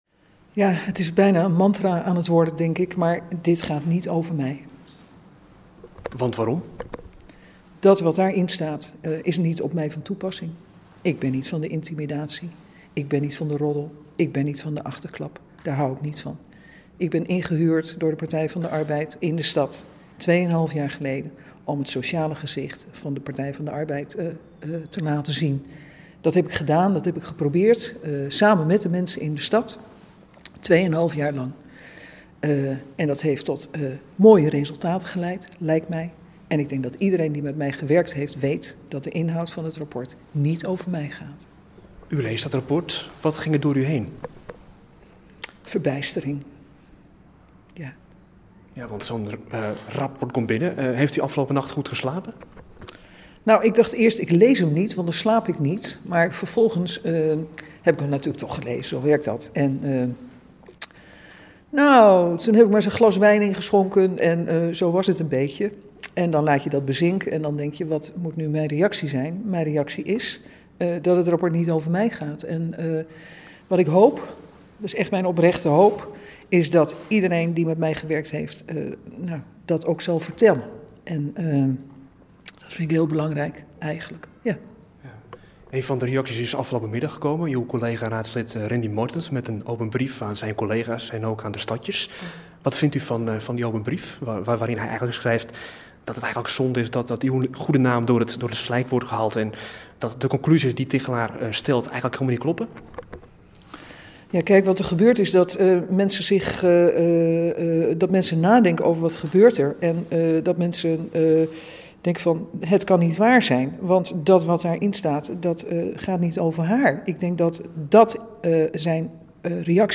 Luister naar wethouder Elly Pastoor in gesprek